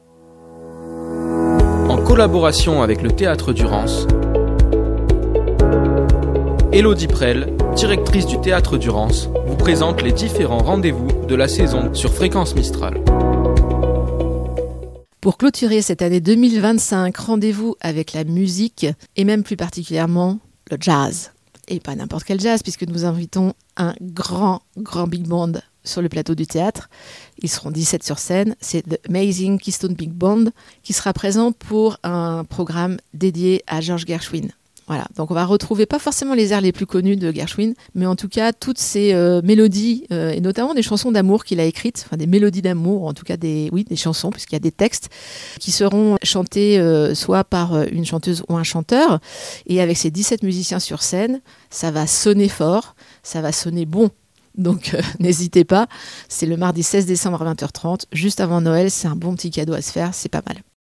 jeunes chanteurs de la scène jazz actuelle